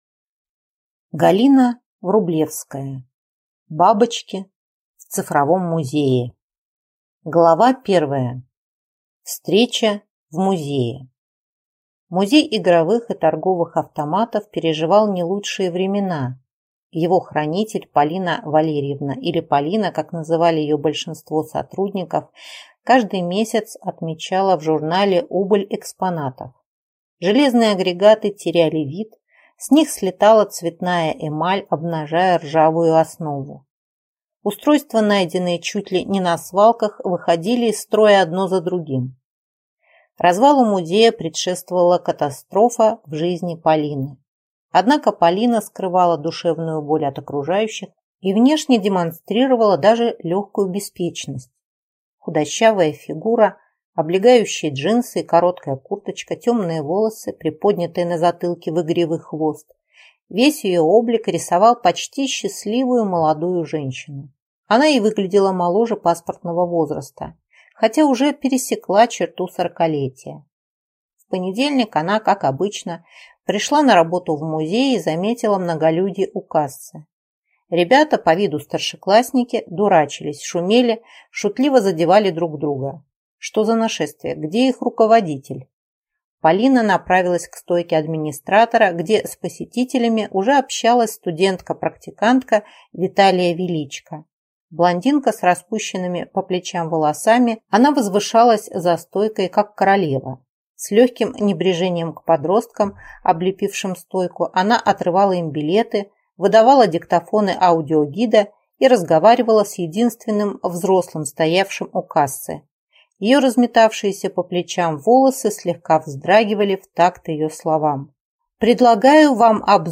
Аудиокнига Бабочки в цифровом музее | Библиотека аудиокниг